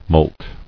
[molt]